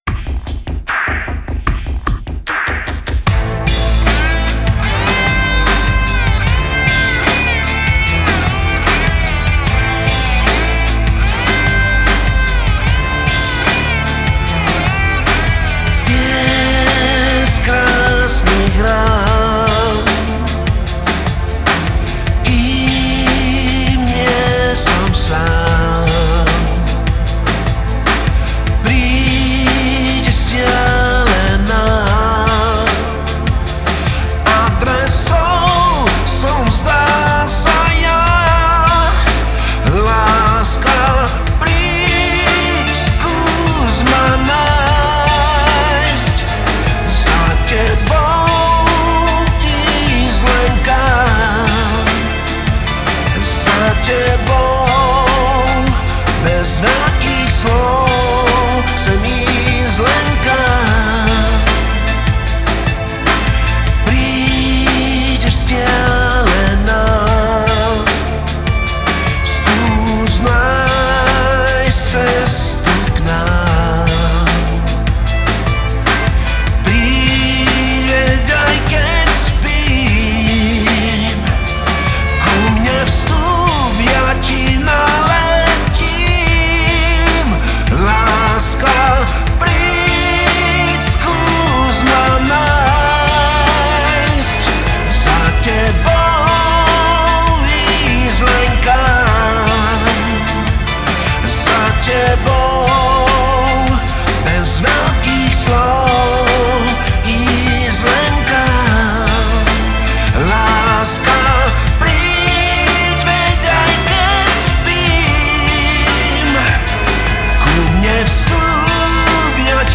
gitary
basgitary
klávesy
bicie